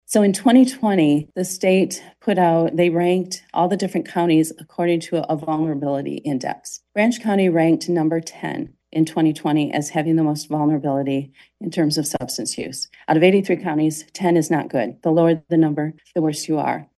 COLDWATER, MI (WTVB) – The Branch County Board of Commissioners heard a pair of requests for the 18-year allocation of opioid settlement funds during last Thursday’s work session.